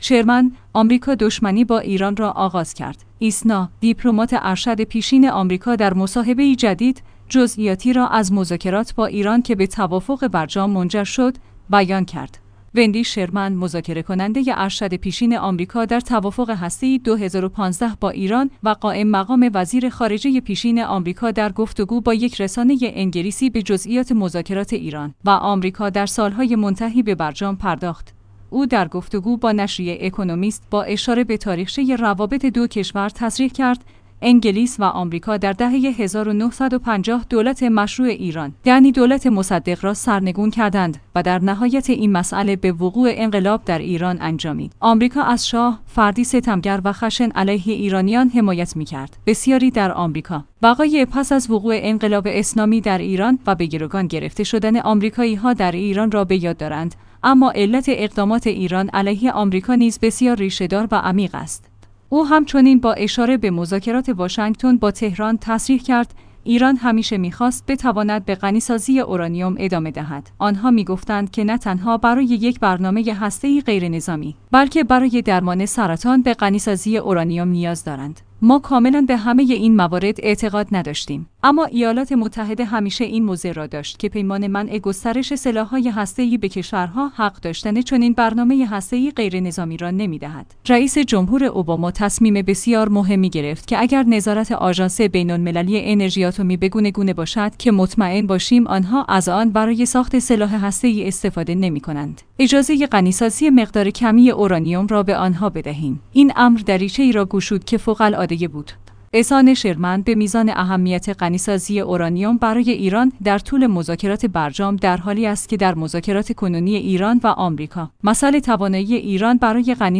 ایسنا/ دیپلمات ارشد پیشین آمریکا در مصاحبه‌ای جدید، جزئیاتی را از مذاکرات با ایران که به توافق برجام منجر شد، بیان کرد.